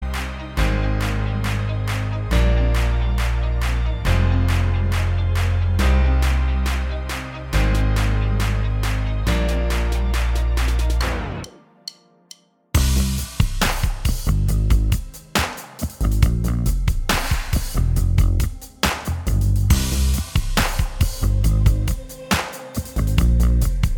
Minus Main Guitars Pop (2010s) 3:08 Buy £1.50